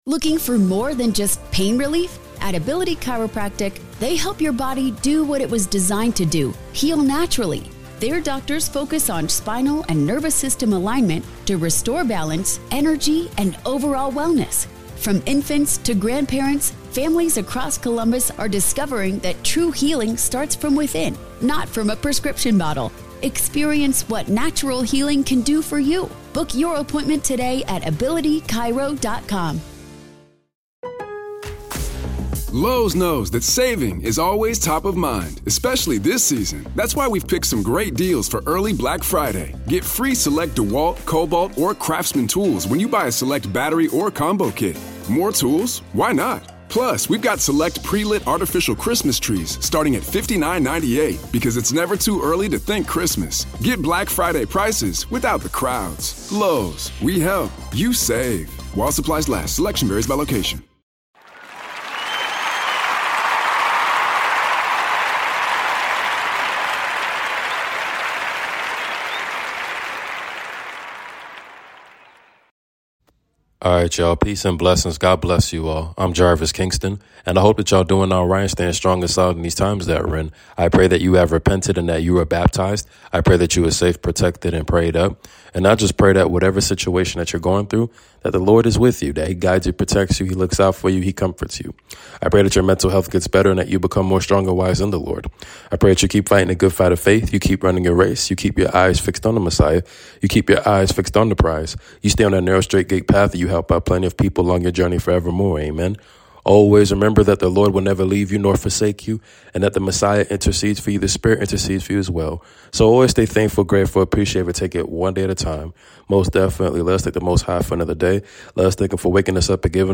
News roundup for this week prayers up